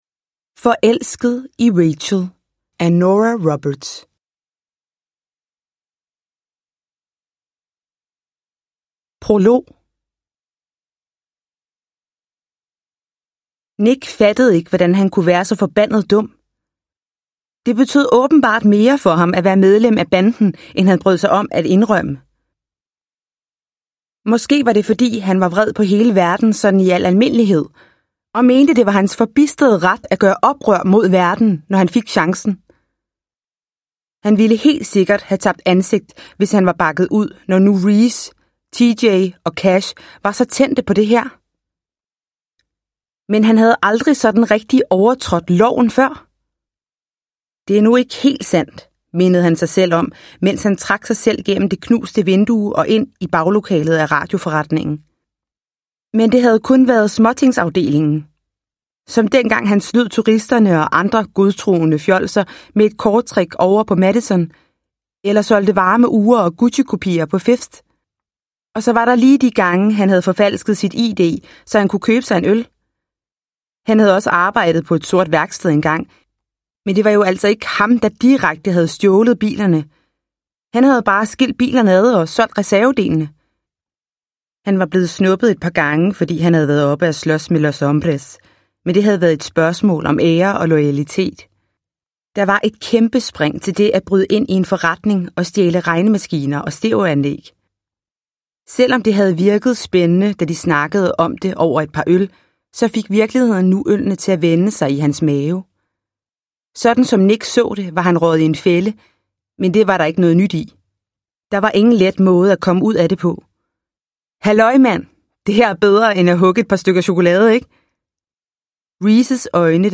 Forelsket i Rachel – Ljudbok – Laddas ner